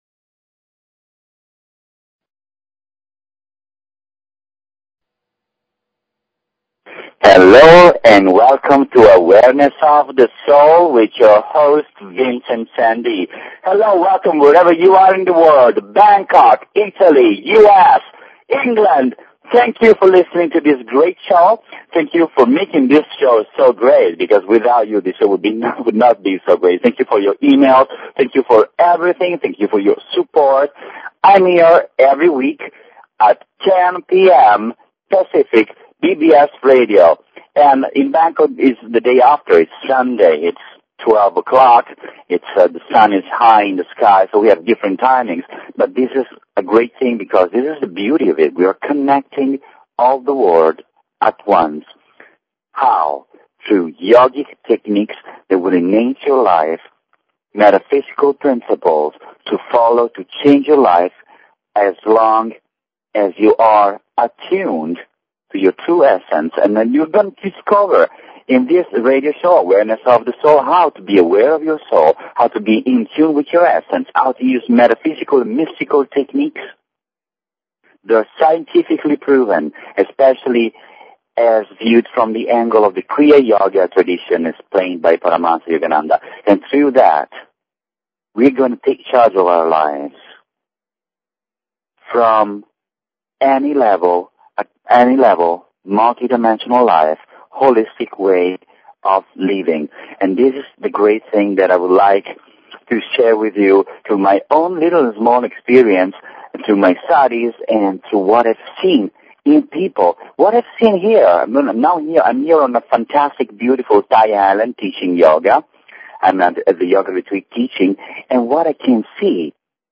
Talk Show Episode, Audio Podcast, Awareness_of_the_Soul and Courtesy of BBS Radio on , show guests , about , categorized as